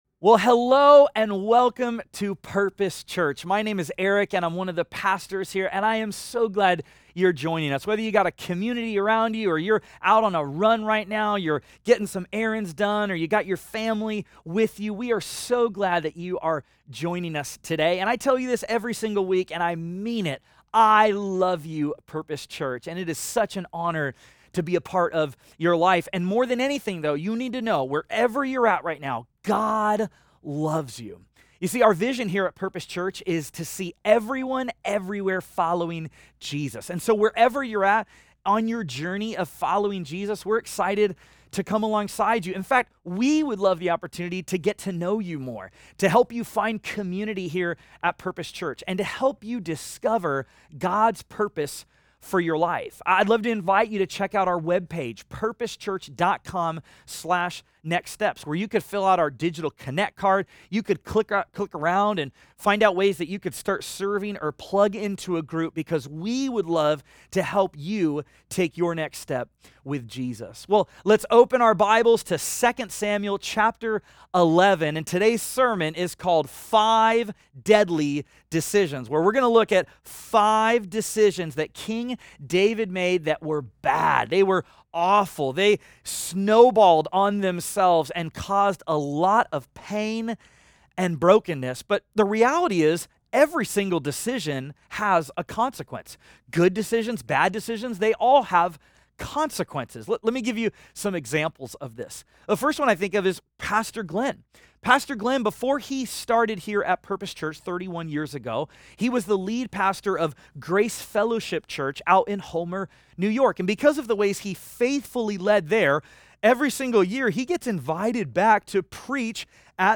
Study Guide | Download Audio File Traditional Worship (In-Person Service)